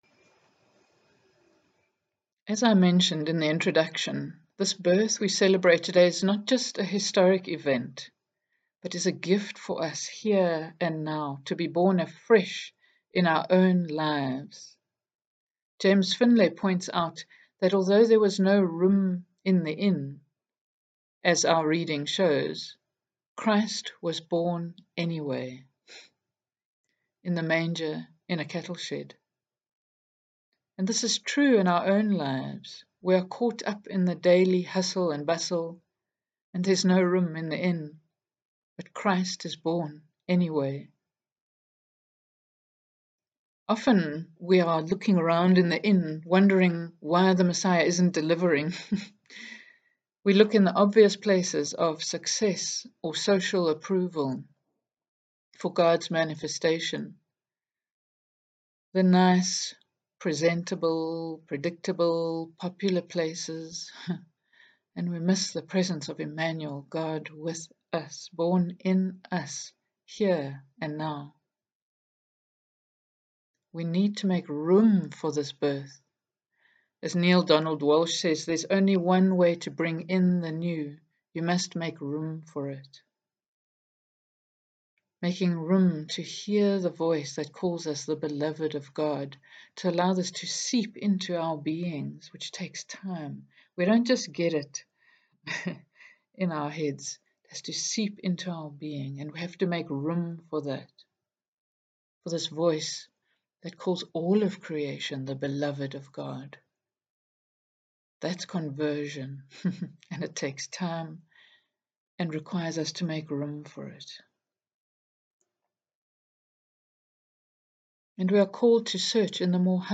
In this online Christmas reflection, we are going to interweave music, readings and reflections as we celebrate the coming of Christ this Christmas time.
Music: Silent night  (sung by Annie Lennox)
Reading 4: Luke 2:25-32
Music: What child is this  (sung by Alanis Morisette)